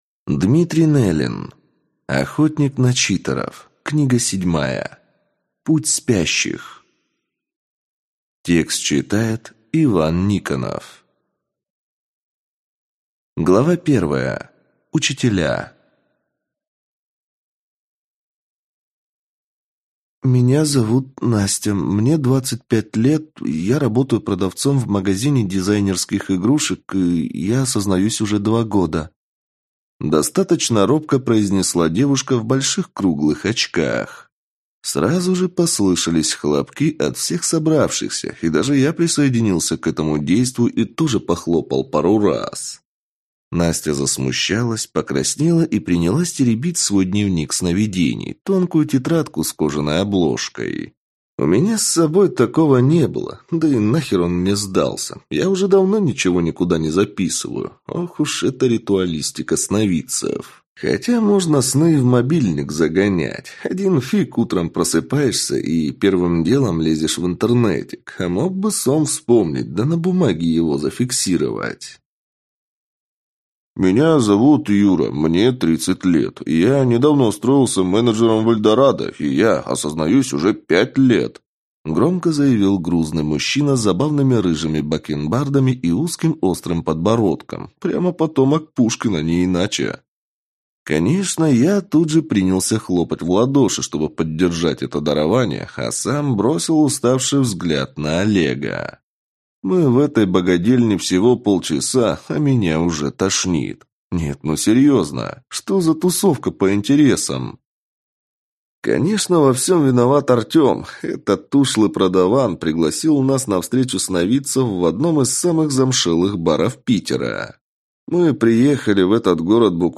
Аудиокнига Путь Спящих | Библиотека аудиокниг